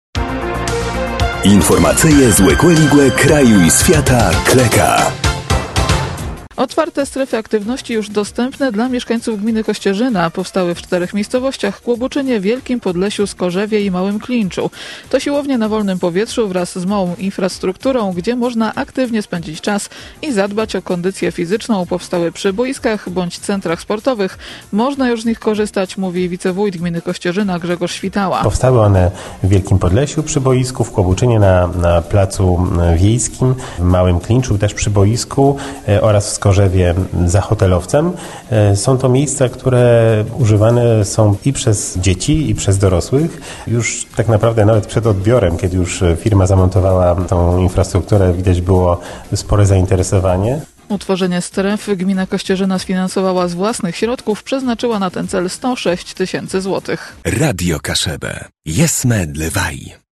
– Można już z nich korzystać – mówi wicewójt gminy Kościerzyna, Grzegorz Świtała.